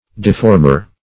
Deformer \De*form"er\, n.